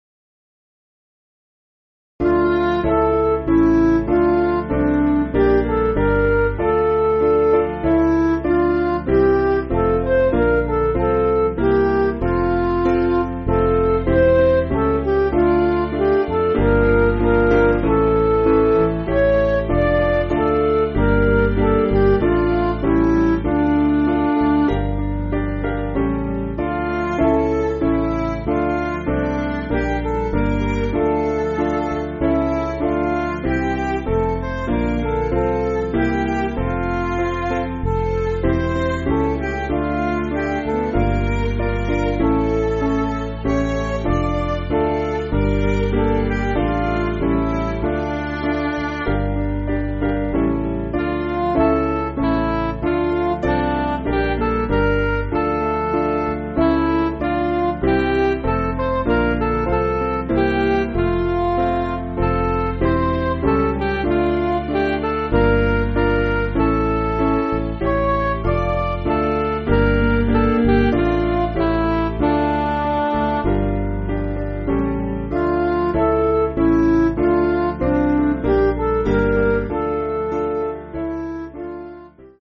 Piano & Instrumental
(CM)   6/Dm